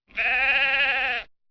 sheep2.wav